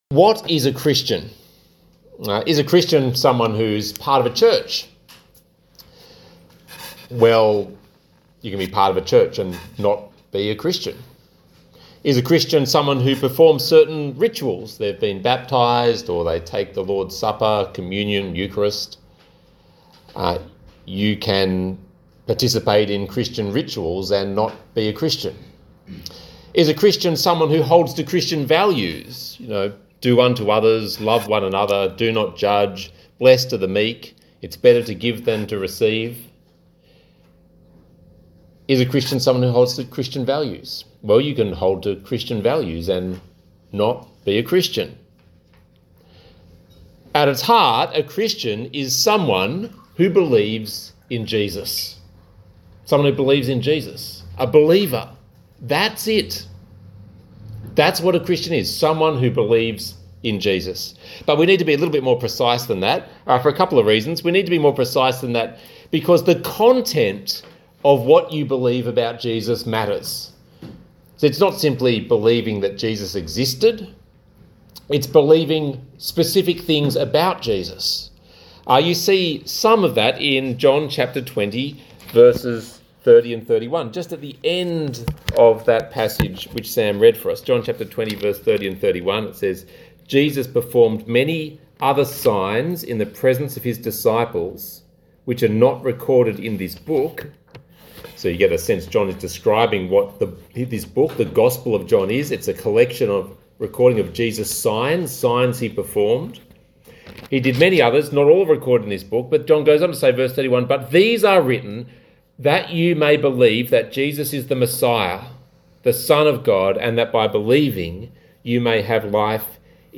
The Resurrection of Jesus Passage: John 20:19-31 Talk Type: Bible Talk